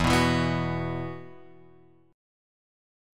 E Suspended 2nd
Esus2 chord {0 2 2 x 0 2} chord